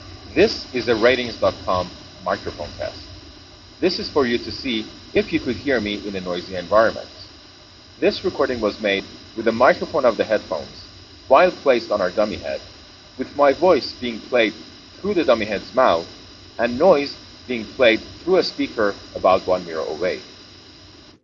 Speech + Pink Noise Audio Sample
As you'd expect, the integrated microphone doesn't perform as well as the boom microphone.
pink noise sample and the
internal-mic-sam-noise.wav